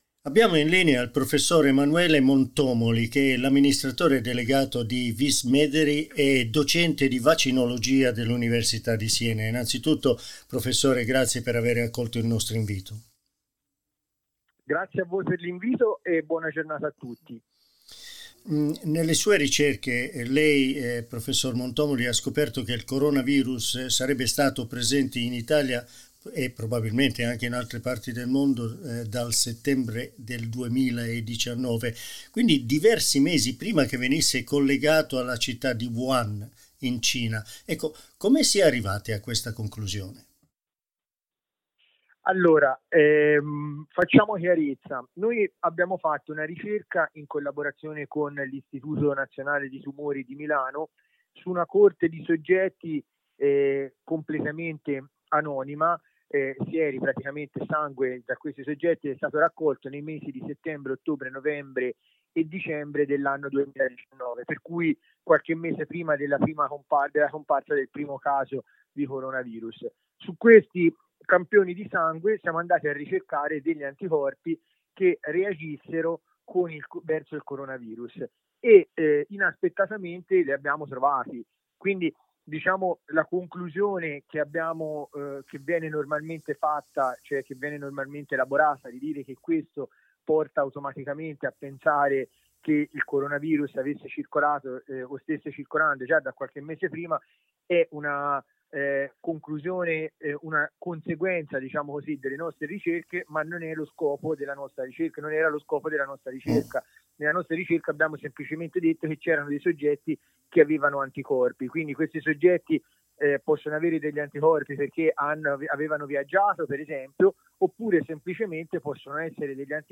Nell’intervista rilasciata a SBS Italian